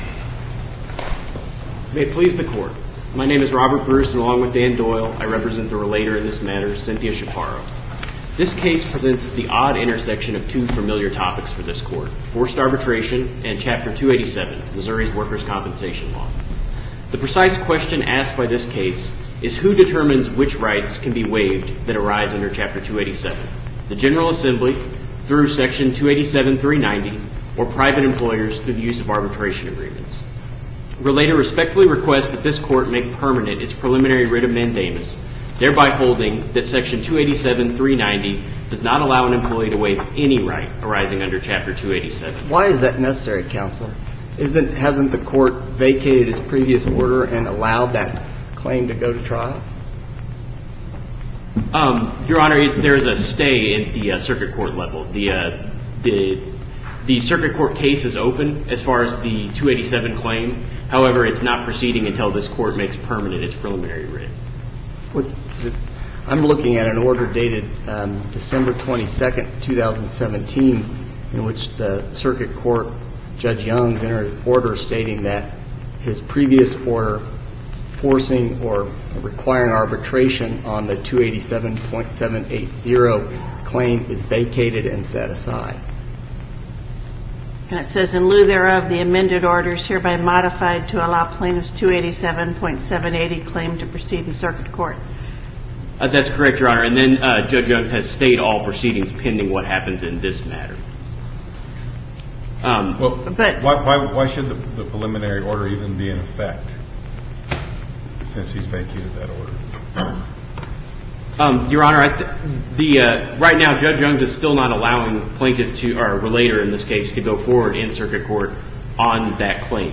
MP3 audio file of arguments before the Supreme Court of Missouri in SC96672